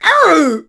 pam_hurt_vo_03.wav